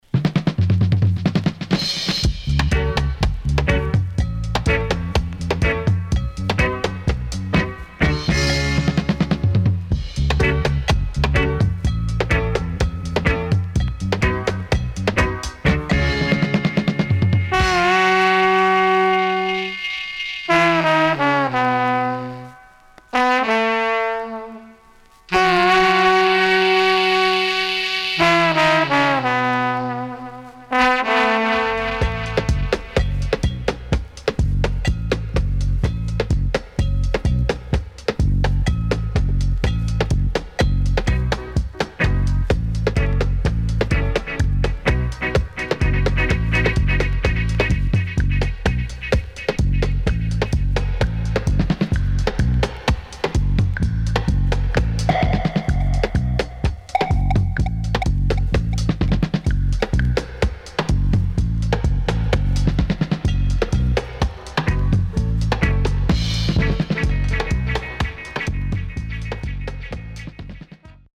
Rare.Killer Stepper Inst & Dubwise.W-Side Good
SIDE A:軽いヒスノイズ入りますが良好です。